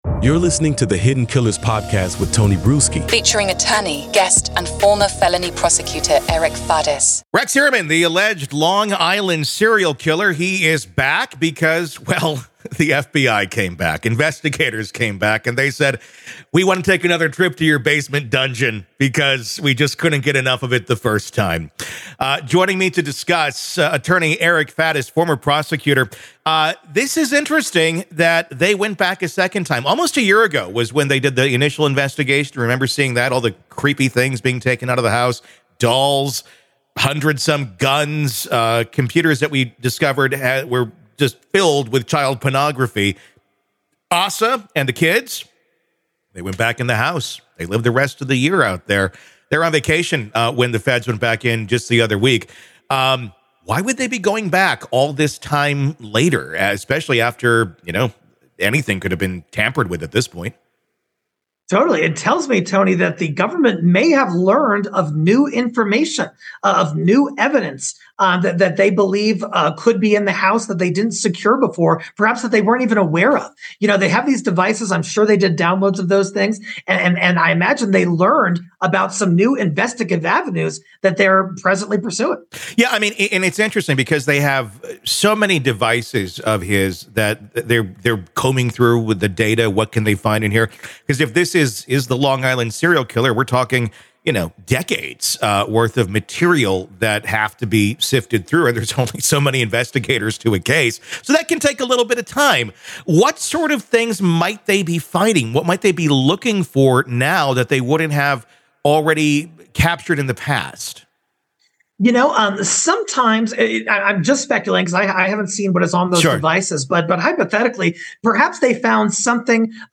The FBI recently returned to Heuermann's home for a second search, indicating they may have discovered new evidence warranting further investigation. The conversation speculated on the possible discovery of additional incriminating materials, including digital diaries or hidden items within the house that corroborate victim testimonies.